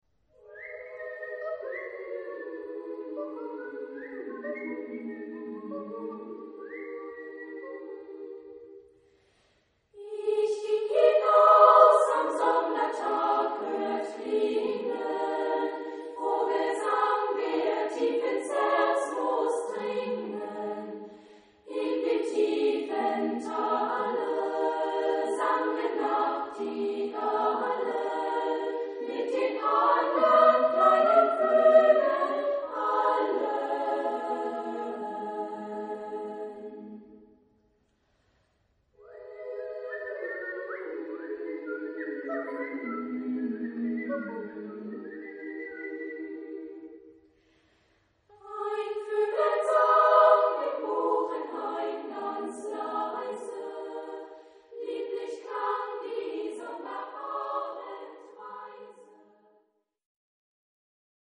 Genre-Style-Forme : Folklore ; Profane
Type de choeur : SSA  (3 voix égale(s) d'enfants OU égales de femmes )
Instruments : Sifflet (1)
Tonalité : sol mode de ré